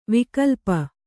♪ vikalpa